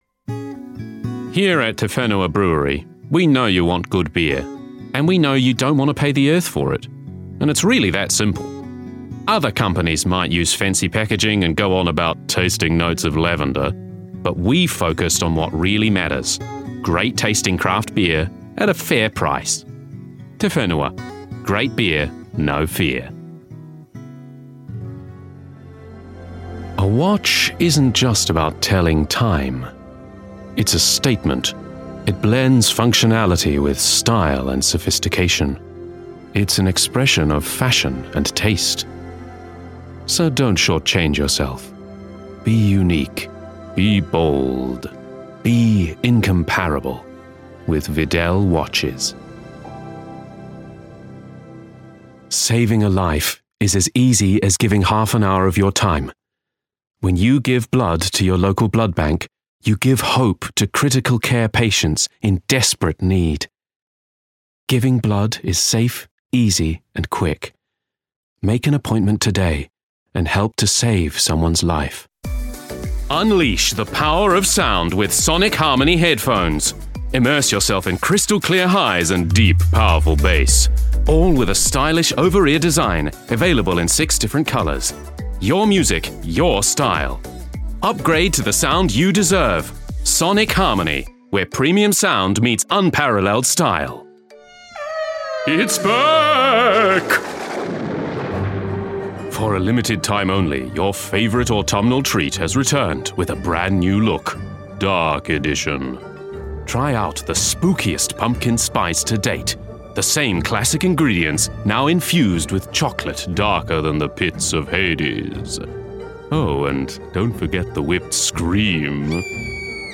Authentic  |  Warm  |  Versatile
Commercial
Highlights Reel
New-Commercial-VO-Reel.mp3